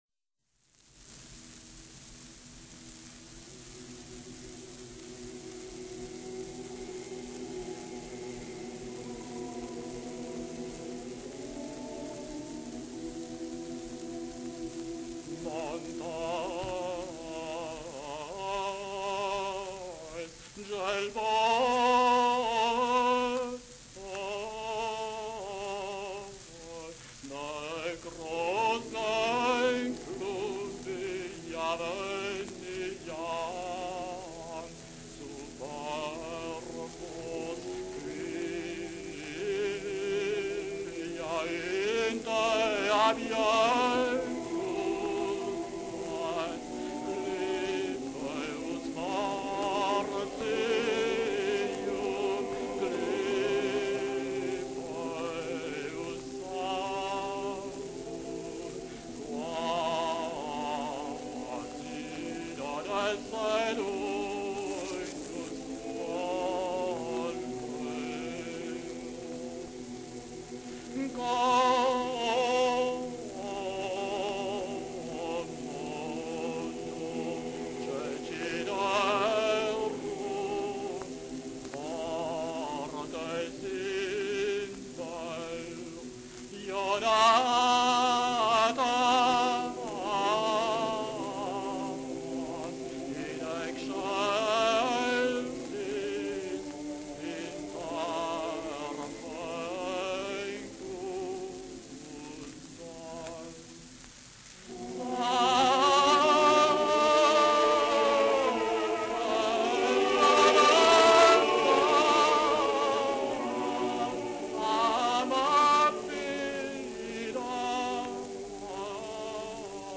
choeur mixte
solo accompagné par voix d’hommes
arrangements polyphoniques
Enregistrement : 1934 - disque 78 tours